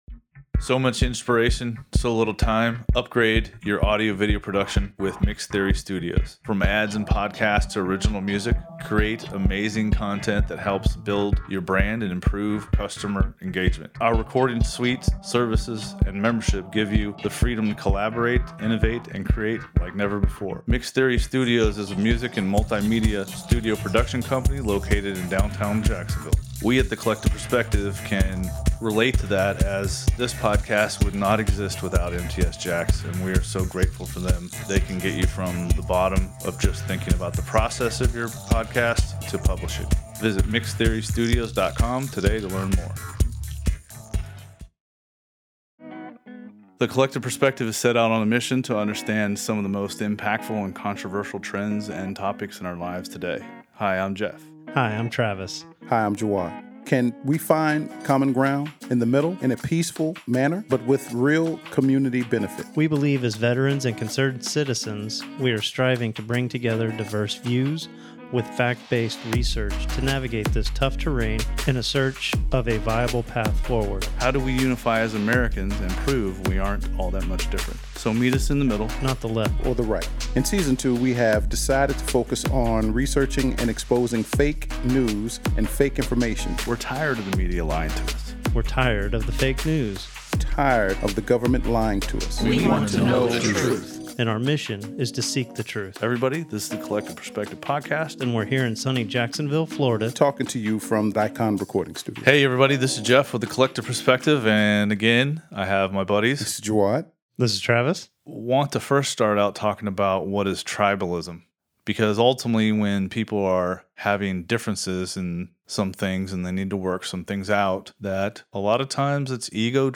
Warning; This podcast was performed by experienced podcasters and long time friends.